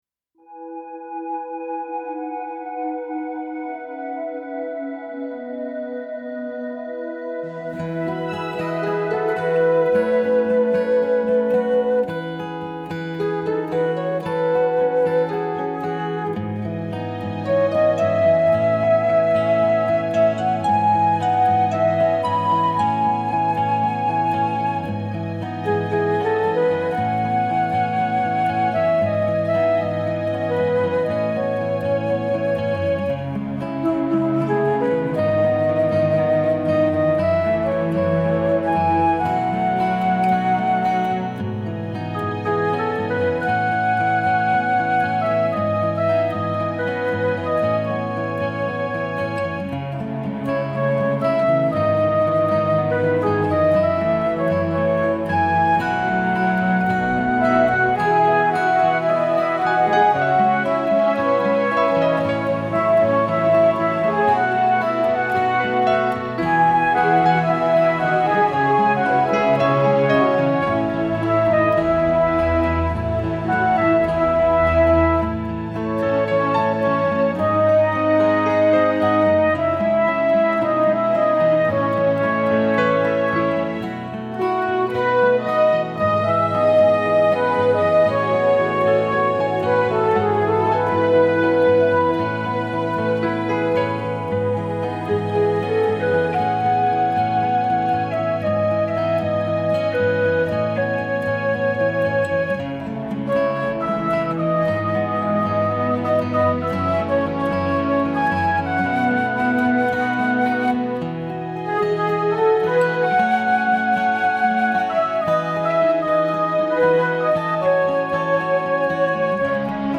More Than You Know #1 Instrumental 2012
more-than-you-know-3-instrumental.mp3